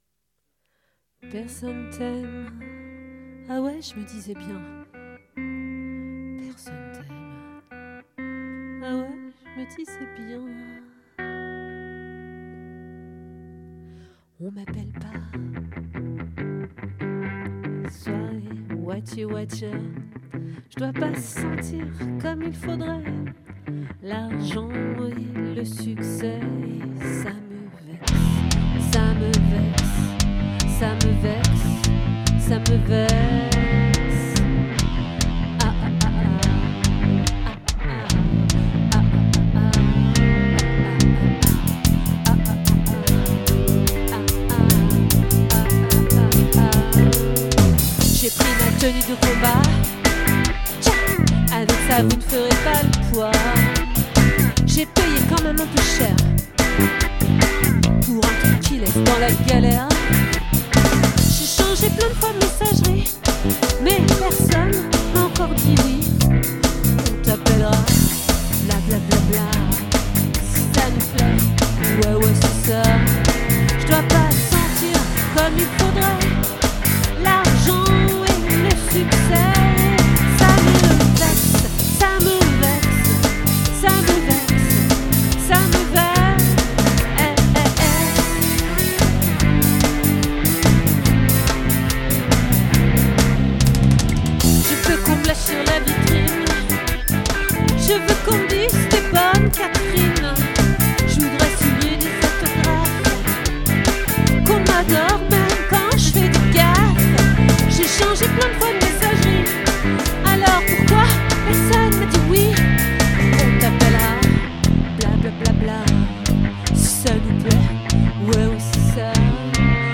🏠 Accueil Repetitions Records_2024_10_14